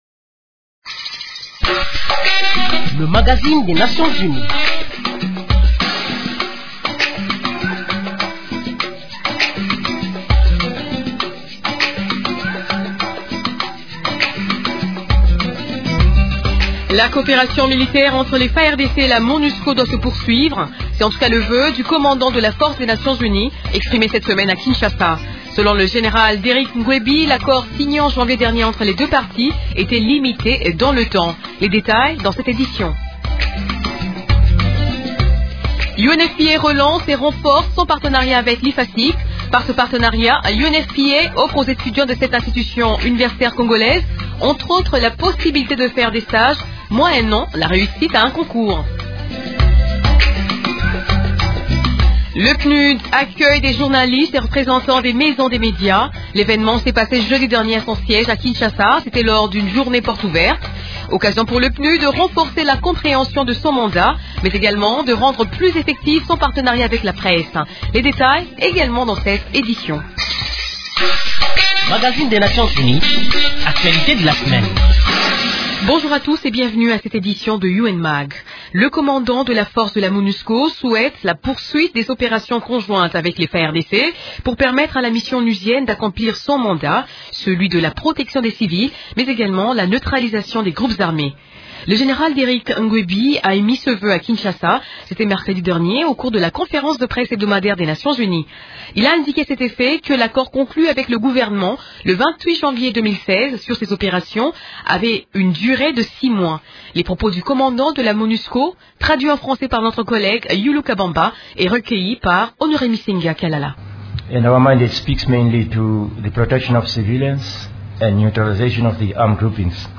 Le commandant de la force de la MONUSCO, Derrick Mgwebi plaide pour la poursuite des opérations conjointes entre les casques bleus et les FARDC pour la protection des civils et la neutralisation des groupes armés. Ce haut officier de la force onusienne a émis ce vœu, mercredi 27 juillet au cours de la conférence de presse hebdomadaire des Nations unies.